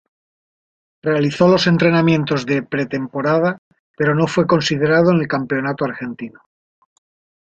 tem‧po‧ra‧da
/tempoˈɾada/